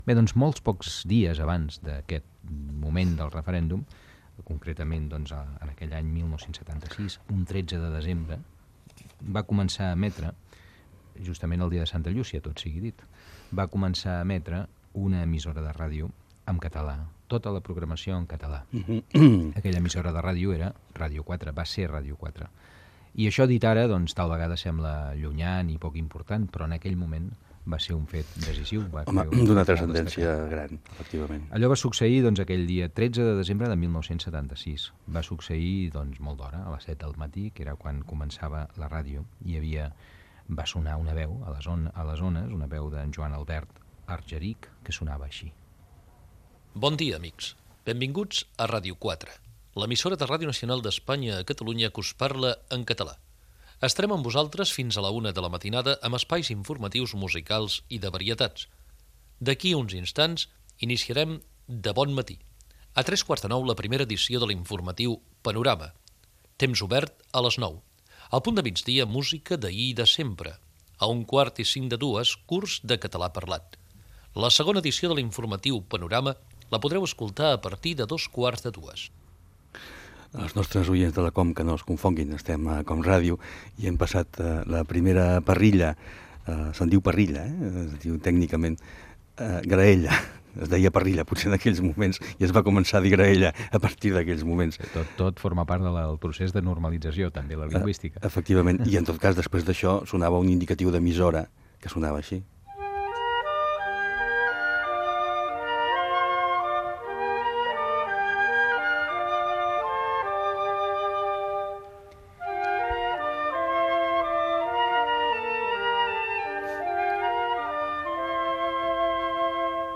Divulgació
FM
Fragment extret de l'arxiu sonor de COM Ràdio.